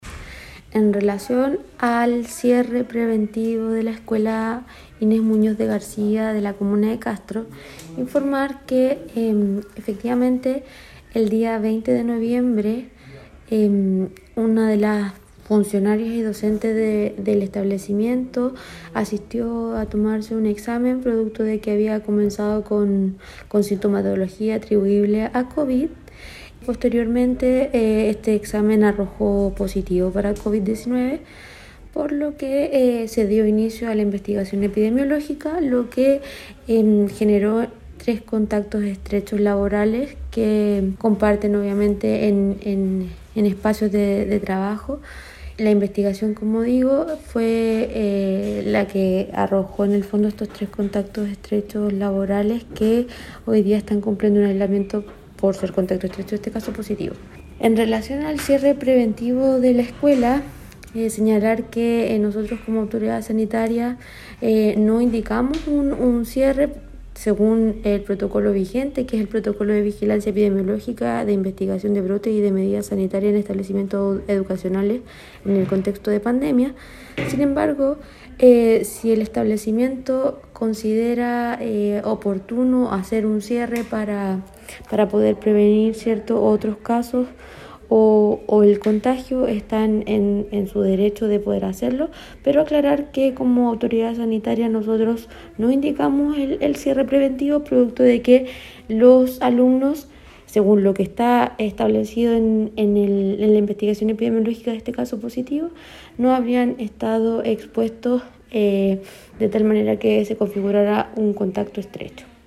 Escuchemos lo señalado por la Autoridad Sanitaria en Chiloé, María Fernanda Matamala, acerca de este caso.